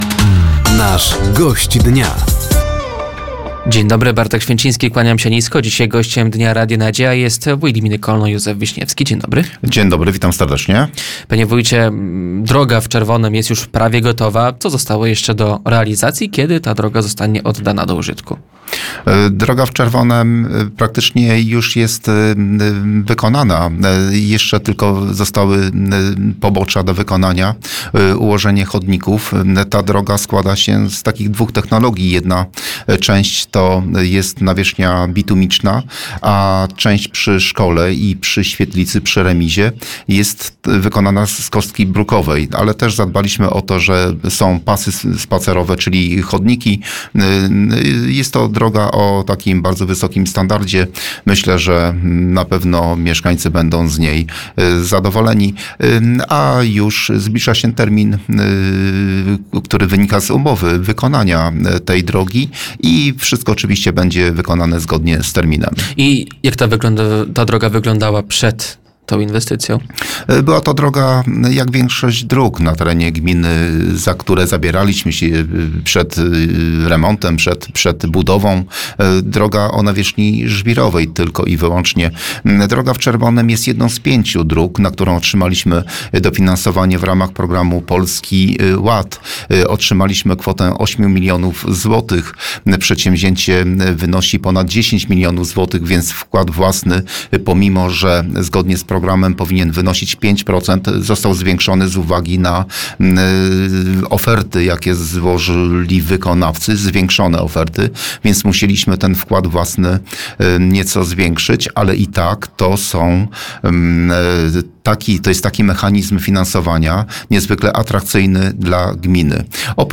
Gościem Dnia Radia Nadzieja był wójt gminy Kolno Józef Wiśniewski. Tematem rozmowy były remonty dróg gminnych, wsparcie finansowe samorządów, inwestycje w gminie oraz dwukadencyjność w samorządach.